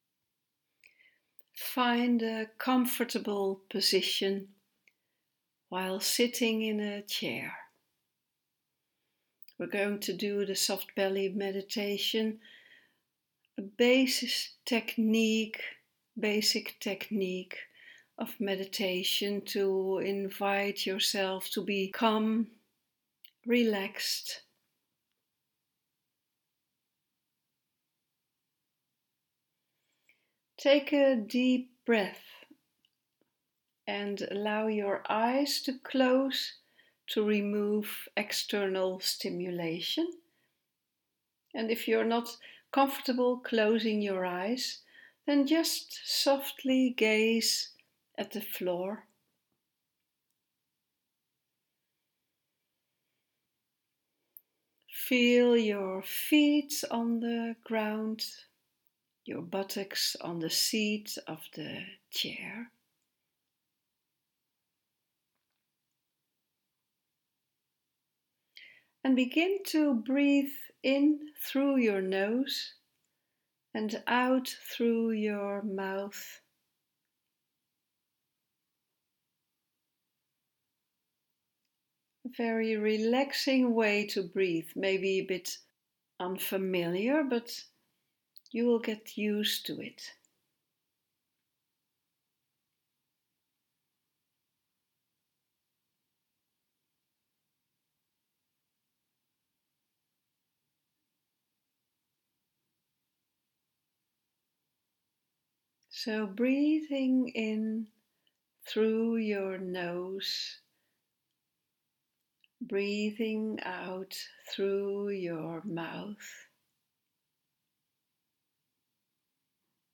English meditation